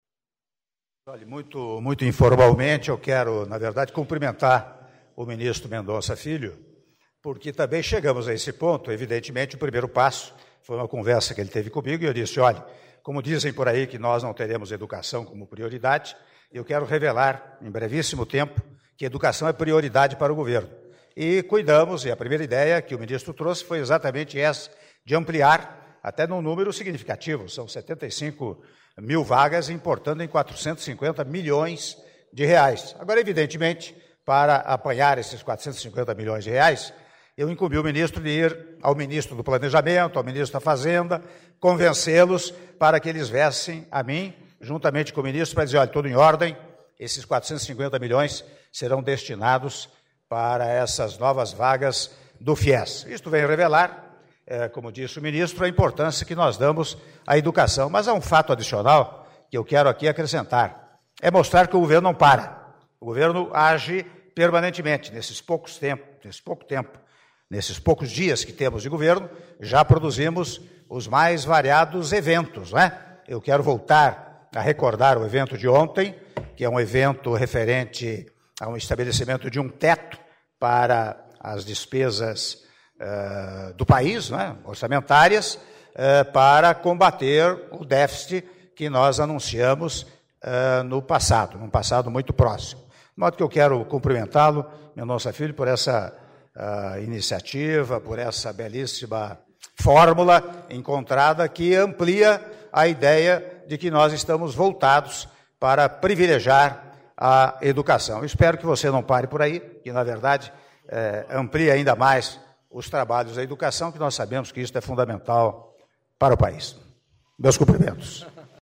Declaração do presidente da República em exercício, Michel Temer, durante assinatura de autorização de criação de 75 mil bolsas do Fies - Brasília/DF (1min58s)